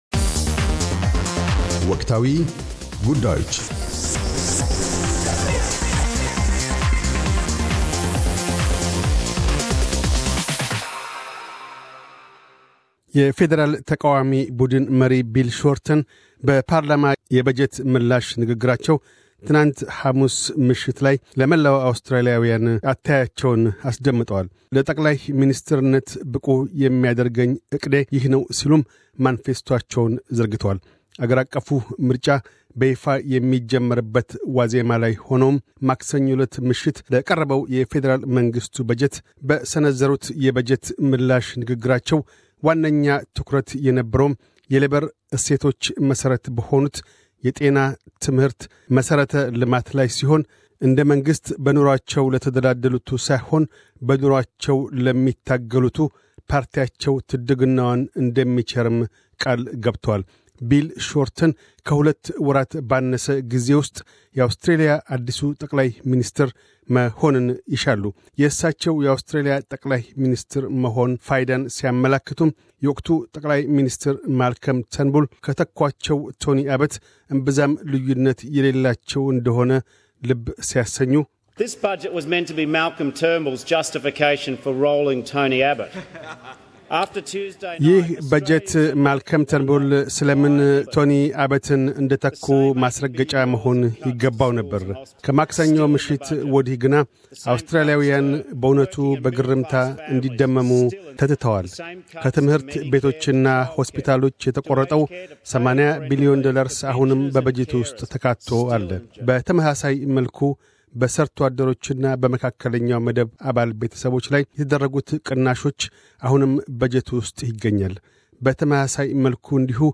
Opposition leader Bill Shorten delivers his budget reply speech on May 5, 2016 in Canberra, Australia.